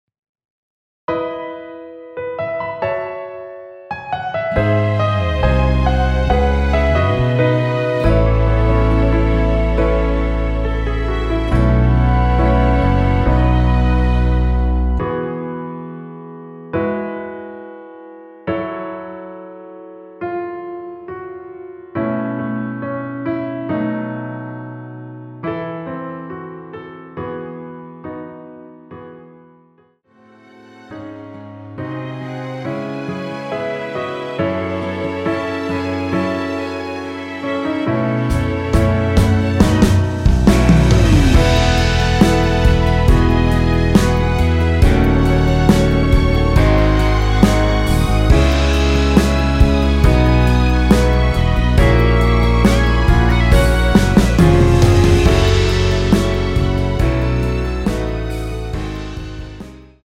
원키에서(+4)올린(1절+후렴)으로 진행되는 MR입니다.(본문의 가사와 미리듣기 확인)
앞부분30초, 뒷부분30초씩 편집해서 올려 드리고 있습니다.
중간에 음이 끈어지고 다시 나오는 이유는